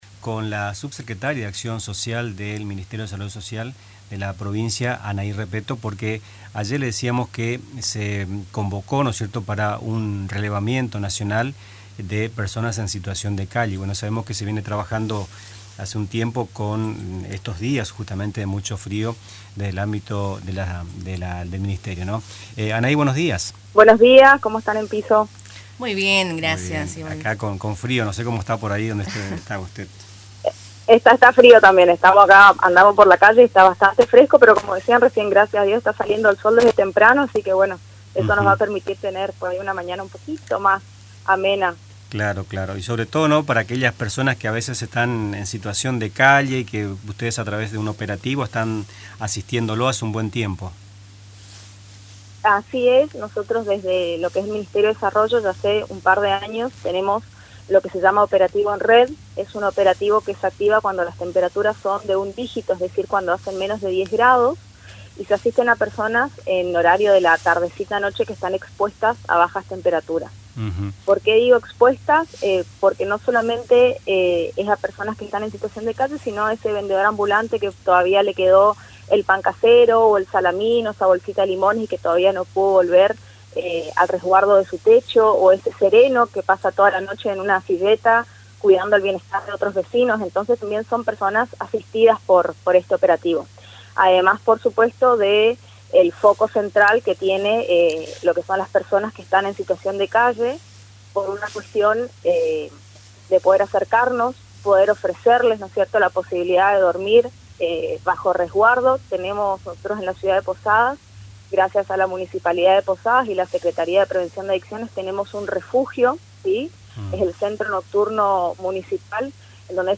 En Nuestras Mañanas entrevistamos a Anahí Repetto, subsecretaria de Acción Social de la provincia, dependiente del Ministerio de Desarrollo Social de la Mujer y Juventud, quien detalló aspectos del pedido realizado por el Ministerio de Capital Humano, a través del cual se llevará a cabo una reunión virtual el próximo 19 de junio. La funcionaria destacó, por otro lado, el trabajo que vienen realizando en Posadas con la red de asistencia a unas 80 personas que duermen en la calle, de manera conjunta con la municipalidad de Posadas.